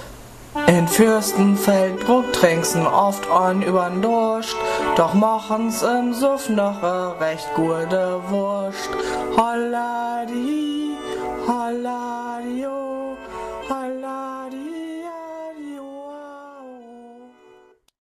Jodeln